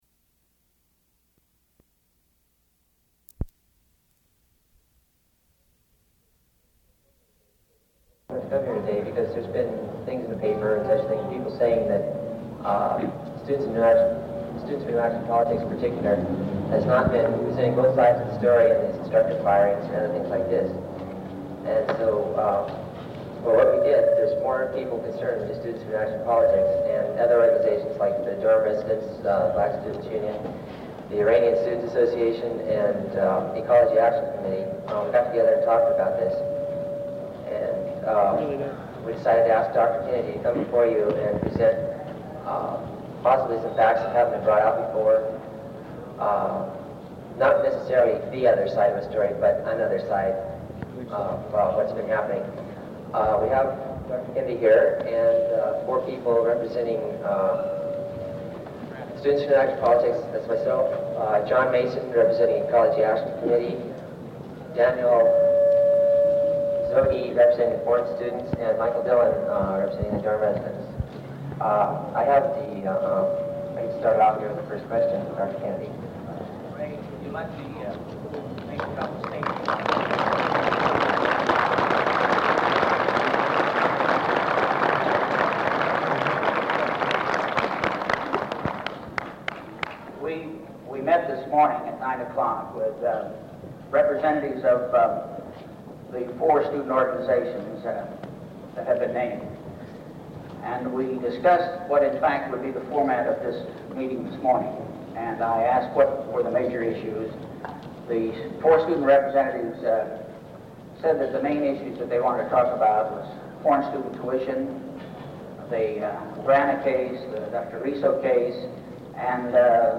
Plaza Rally #1 - Spring 1971